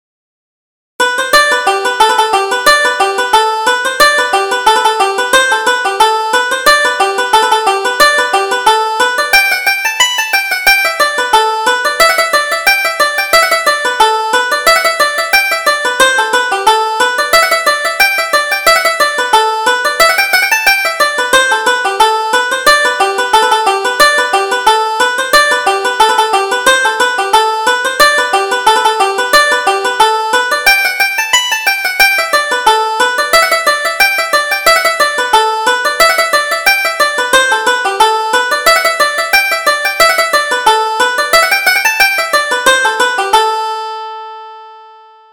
Reel: You Never Saw Her Equal